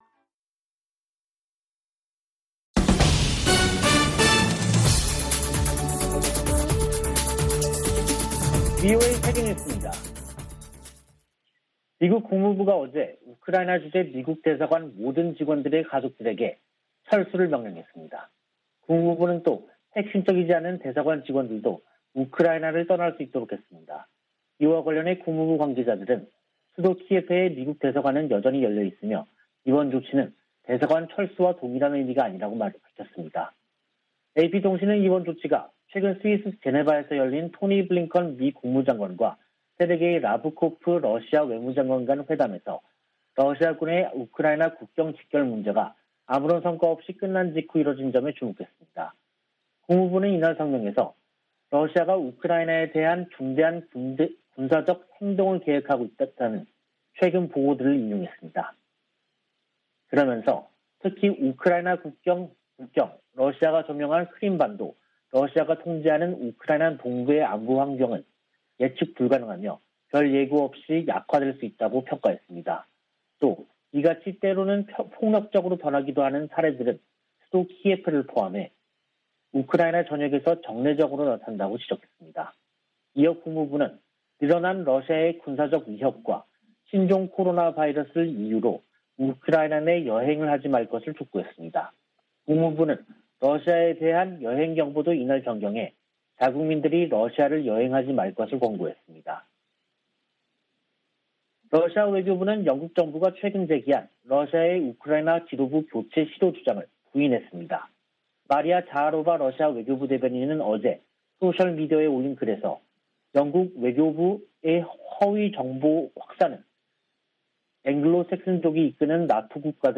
VOA 한국어 간판 뉴스 프로그램 '뉴스 투데이', 2022년 1월 24일 3부 방송입니다. 미-일 화상 정상 회담에서 북한의 잇따른 탄도미사일 시험 발사를 규탄하고 긴밀한 공조를 다짐했습니다. 미 국무부는 핵과 대륙간탄도미사일 실험 재개 의지로 해석된 북한의 최근 발표와 관련해 외교와 압박을 병행하겠다는 원칙을 확인했습니다. 미 국방부는 북한의 무기실험 재개 시사에 우려를 나타내면서도 외교적 관여 기조에는 변화가 없다고 밝혔습니다.